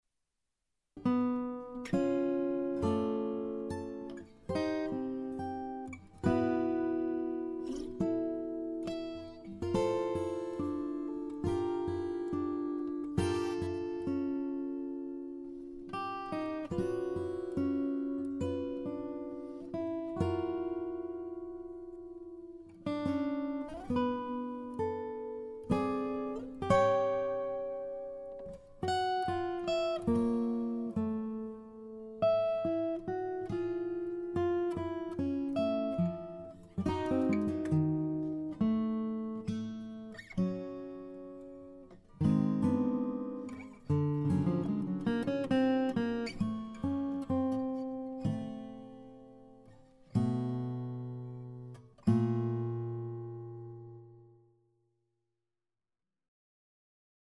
for two guitars